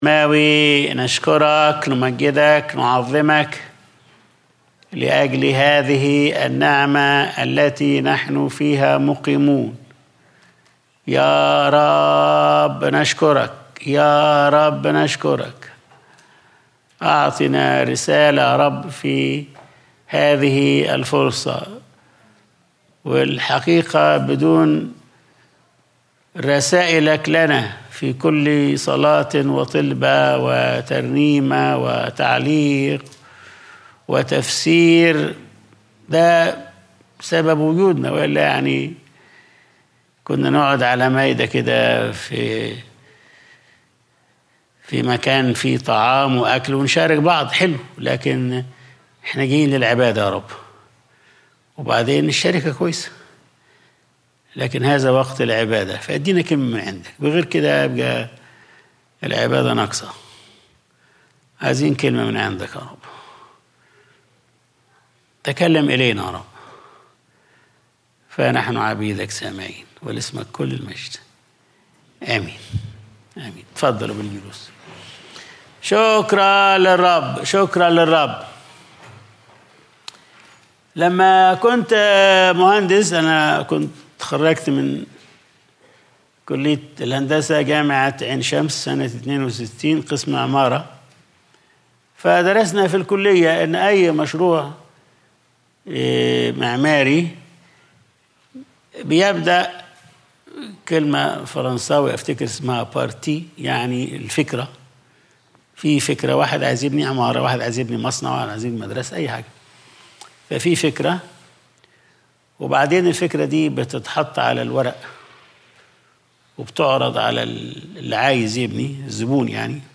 Sunday Service | إنتظر الرب واصبِر له